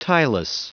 Prononciation du mot tieless en anglais (fichier audio)
Prononciation du mot : tieless